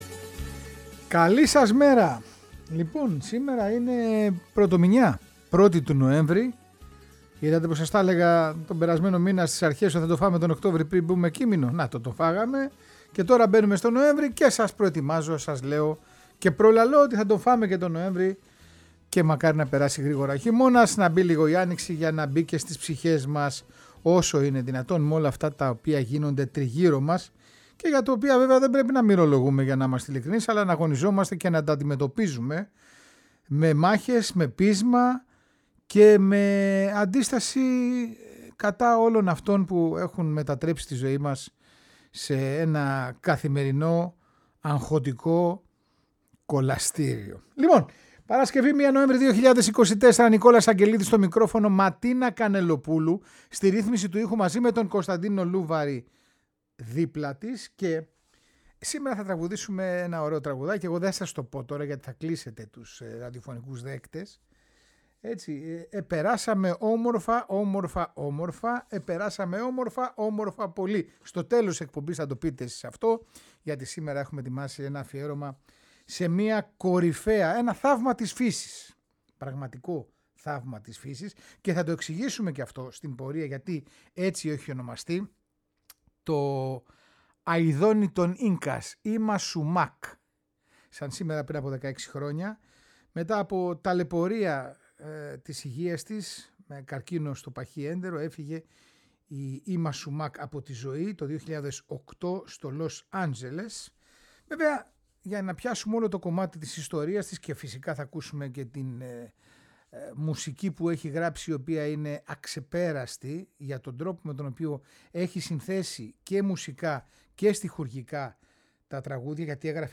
Η Περουβιανή σοπράνο έζησε μια έντονη ζωή γεμάτη δυσκολίες και αγώνες και κατάφερε να καταξιωθεί σε διεθνές επίπεδο, με πείσμα, επιμονή και σκληρή δουλειά…
Τραγουδούσε σε ρυθμούς τζαζ με λατινοαμερικανική και πολυνησιακή επιρροή ήχων και μάγευε τα πλήθη σε κάθε γωνιά του πλανήτη.